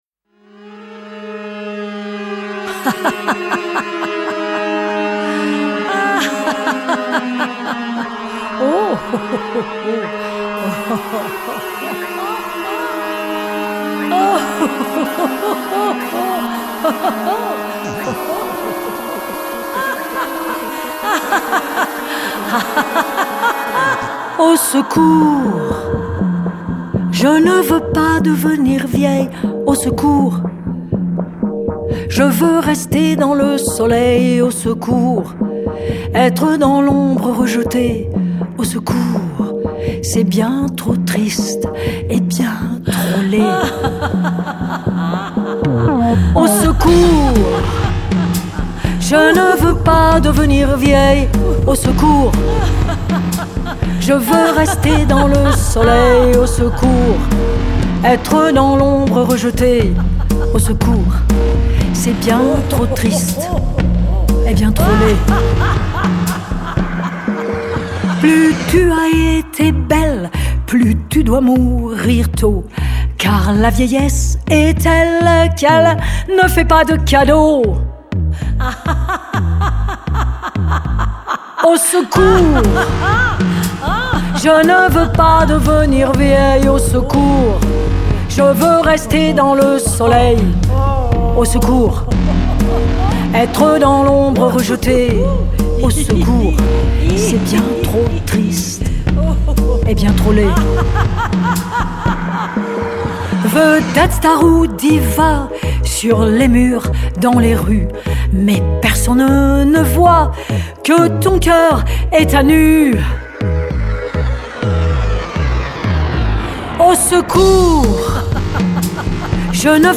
la vieillesse…. sur une musique électro originale.